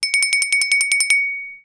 ZVONEK2.WAV